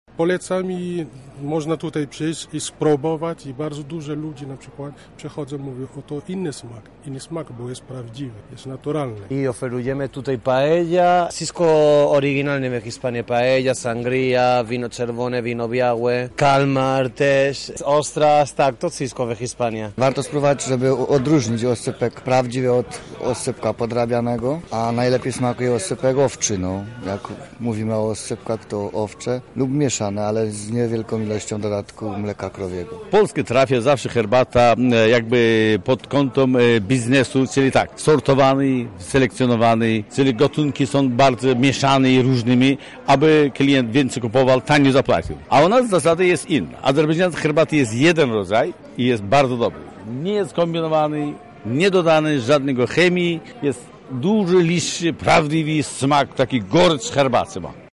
Nasi Reporterzy byli na miejscu i sprawdzili, co można będzie kupić.